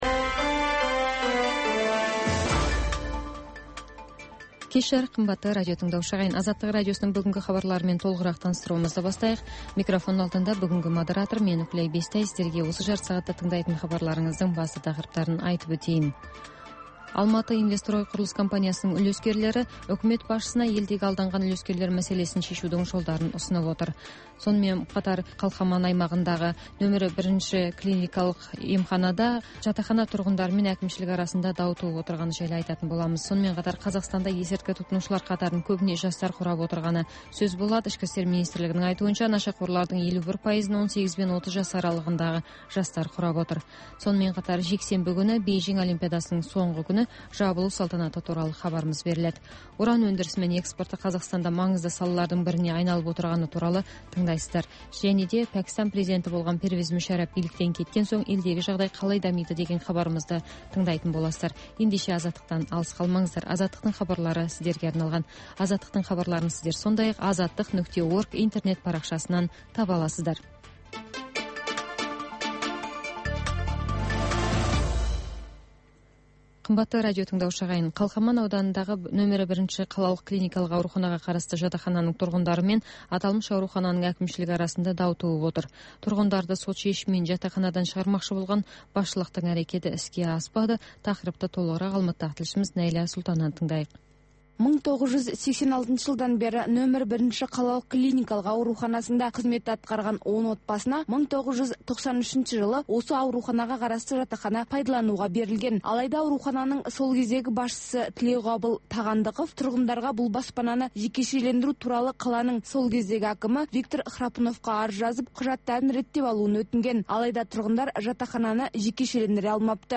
Бүгінгі күннің өзекті тақырыбына талқылаулар, пікірталас, оқиға ортасынан репортаж, сарапшылар талдауы мен қарапайым азаматтардың еркін пікірі, спорт жаңалықтары.